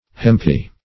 hempy - definition of hempy - synonyms, pronunciation, spelling from Free Dictionary Search Result for " hempy" : The Collaborative International Dictionary of English v.0.48: Hempy \Hemp"y\, a. Like hemp.
hempy.mp3